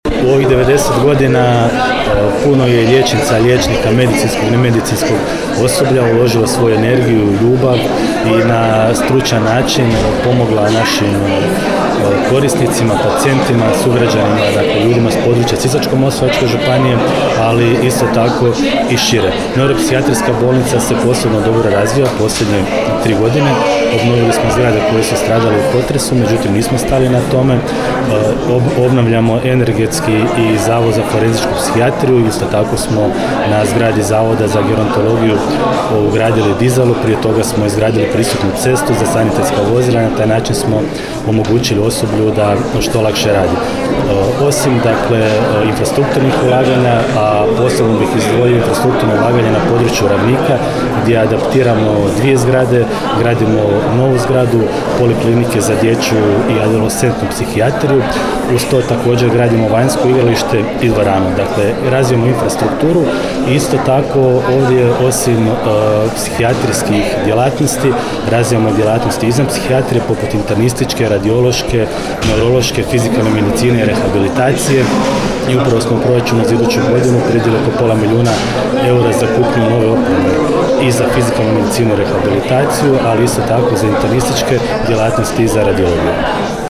U povodu obilježavanja 90 godina Neuropsihijatrijske bolnice „dr. Ivan Barbot” Popovača u petak, 22. studenog 2024. godine, u Domu kulture u Popovači održana je prigodna svečanost.
Više o tome, župan Ivan Celjak